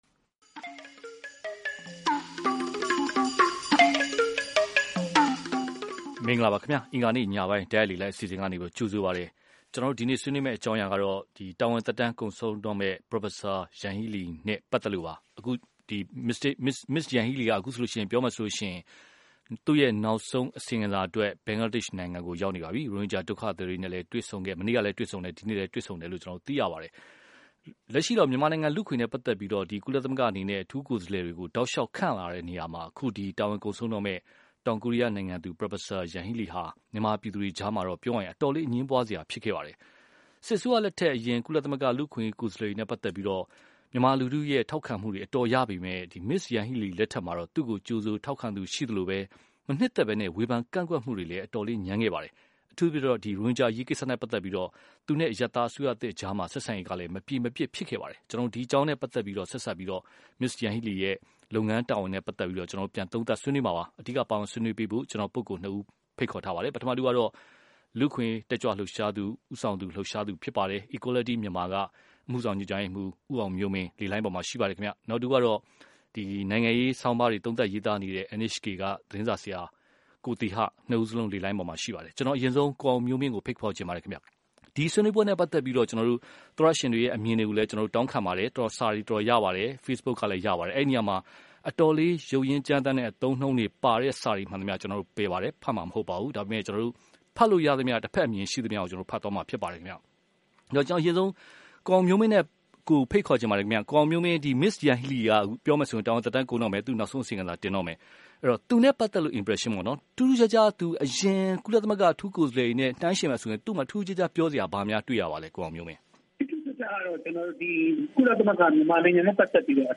တာဝန်သက်တမ်းကုန်တော့မယ့် Prof. Yanghee Lee(တိုက်ရိုက်လေလှိုင်း)